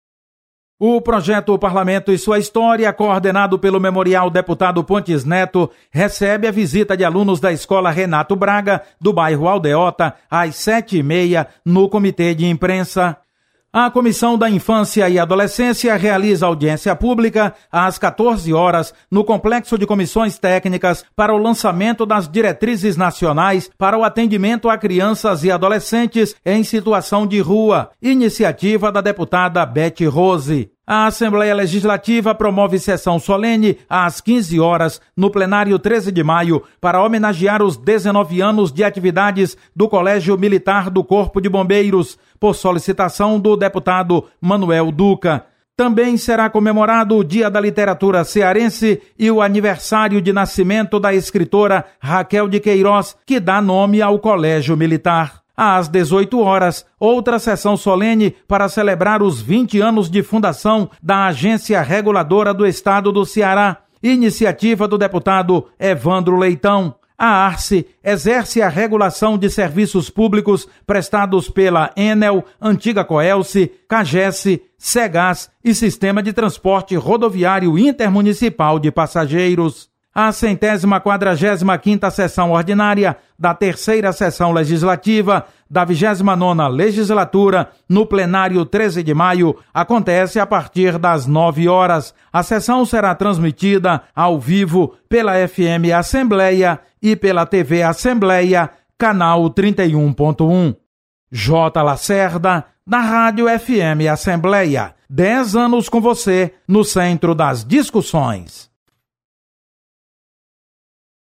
Você está aqui: Início Comunicação Rádio FM Assembleia Notícias Agenda